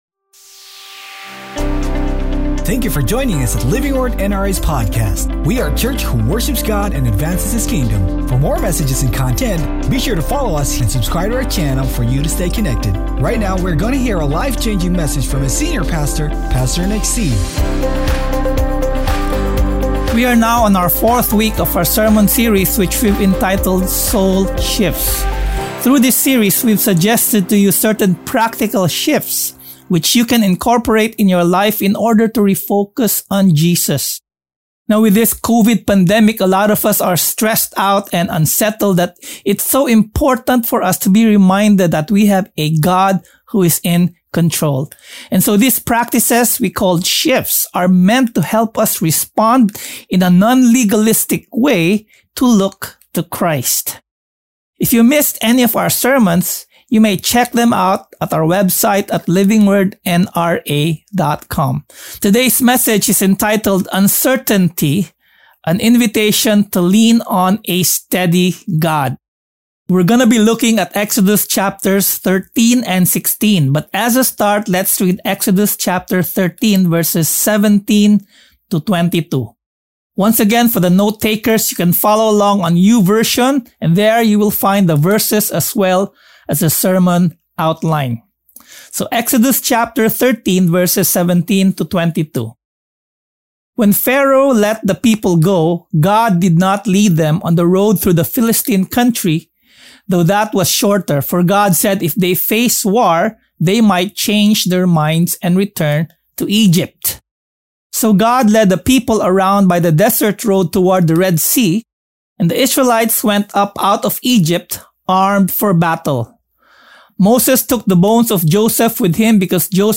Sermon #4: UNCERTAINTY: AN INVITATION TO LEAN ON A STEADY GOD